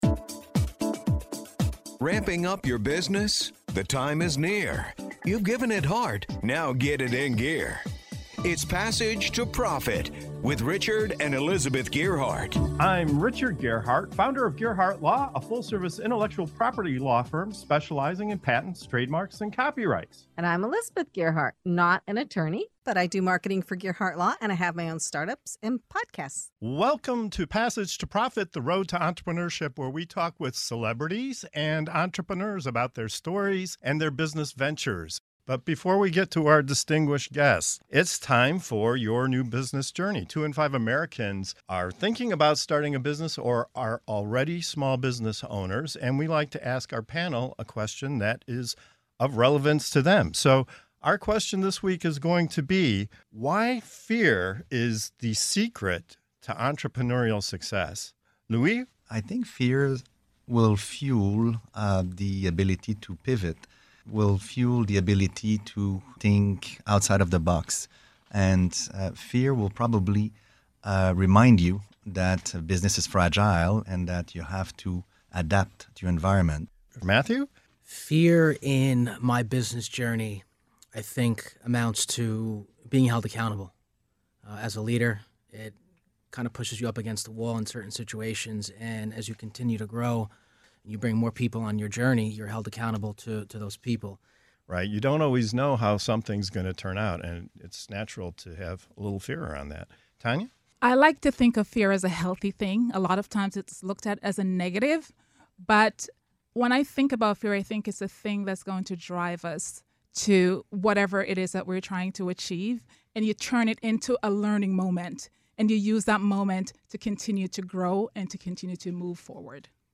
In this segment of "Your New Business Journey" on Passage to Profit Show, we dive into an unexpected truth: fear might just be the secret weapon for entrepreneurial success. Our panel of business minds shares how fear fuels creativity, accountability, resilience, and growth.